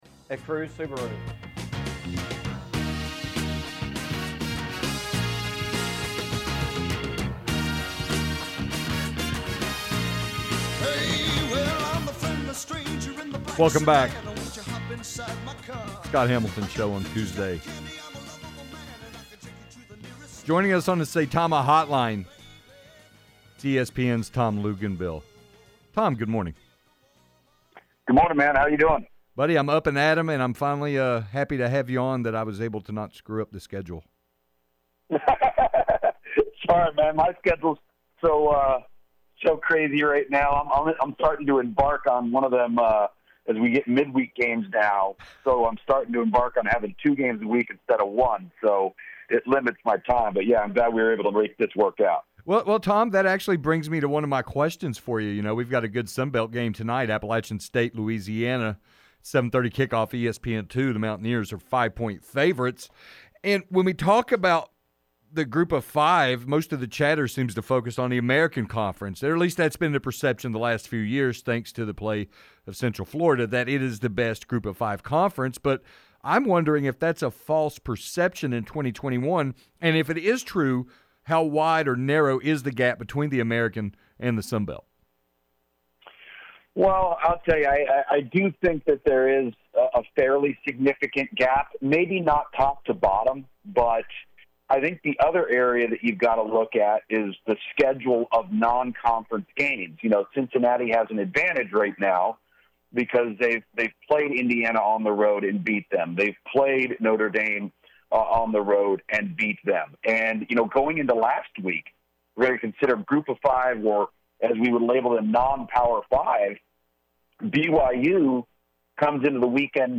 Tom Luginbill Interview
Tom-Luginbill-Interview-.mp3